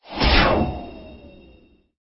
020-Teleport03.mp3